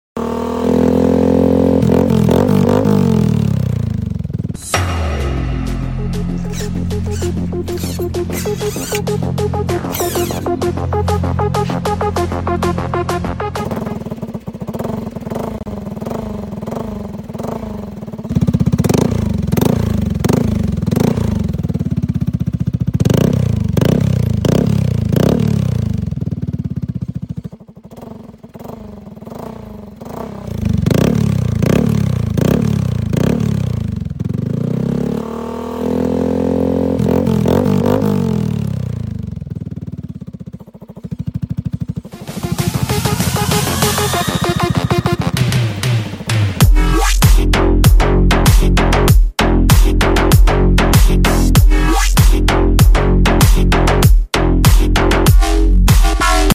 Tridente Scorpion Installed on Yamaha sound effects free download
Tridente Scorpion Installed on Yamaha Aerox Turbo 2025